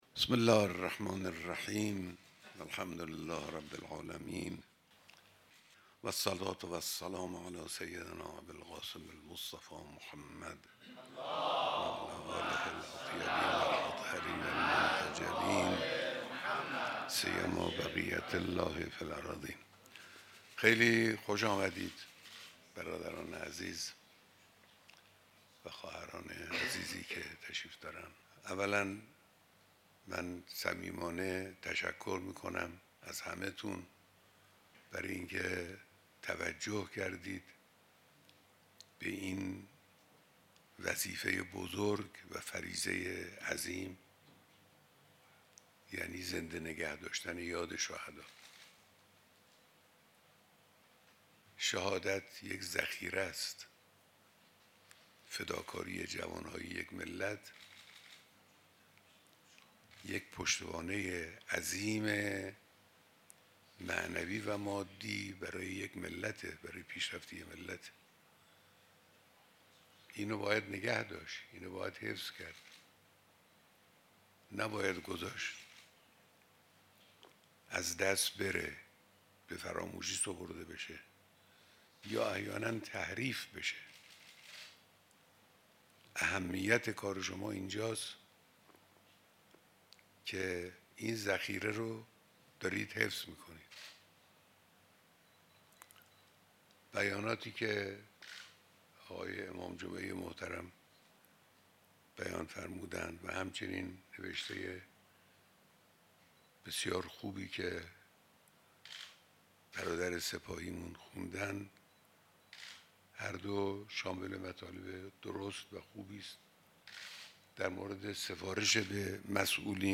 بیانات در دیدار اعضای ستاد کنگره ملی شهدای استان کهگیلویه و بویراحمد